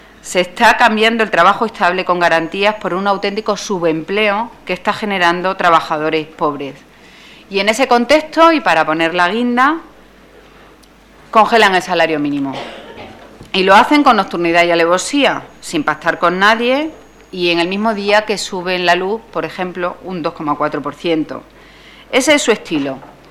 Pilar Lucio en la comisión de empleodel 16/01/2014